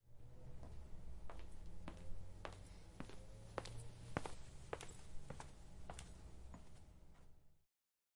脚步声, 运动鞋, 瓦片, 快速
描述：硬底运动鞋在瓷砖地板上，节奏快。使用舒尔SM58麦克风录制。
Tag: 脚步 运动鞋 踩着 步骤 一步 混凝土 培训 运行 凳子 地板 运行 瓷砖